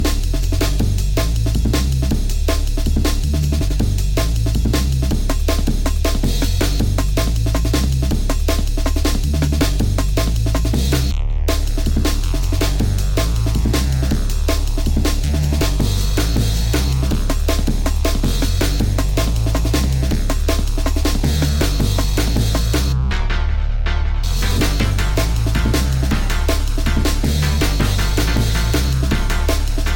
TOP > Jungle